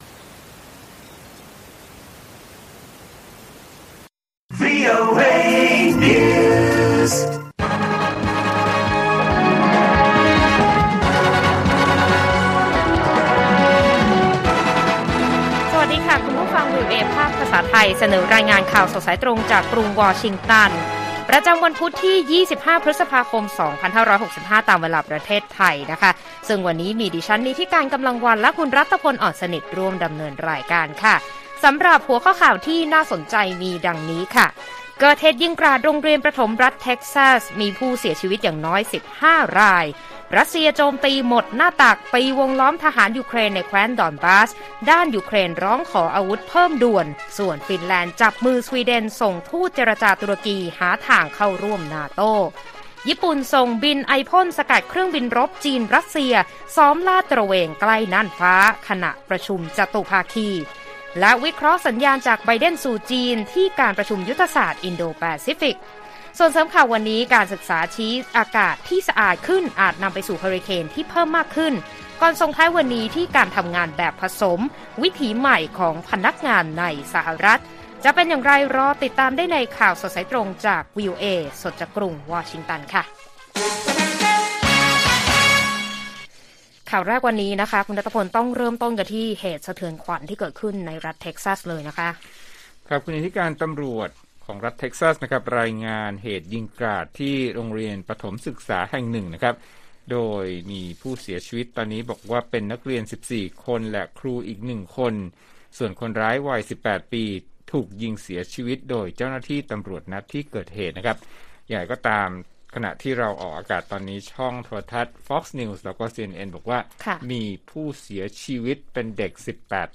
ข่าวสดสายตรงจากวีโอเอ ไทย พุธ ที่ 25 พ.ค.2565